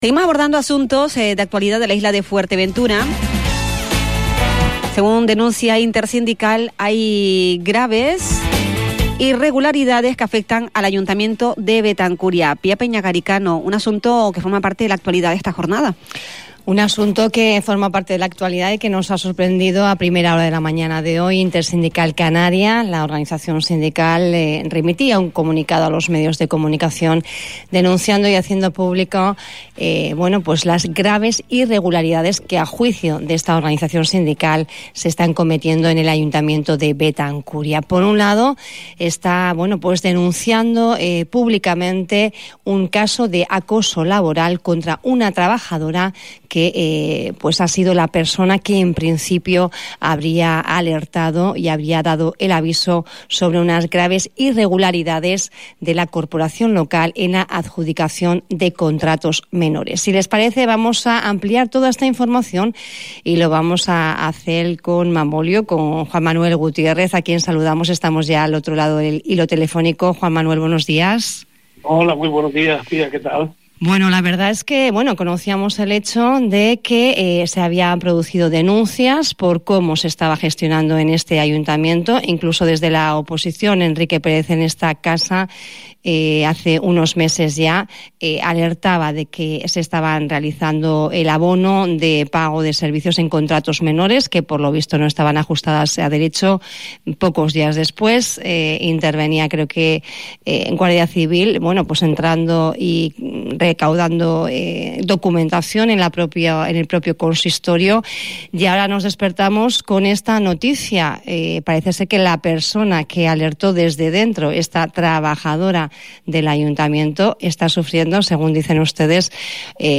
una entrevista en La Voz de Fuerteventura, en Radio Insular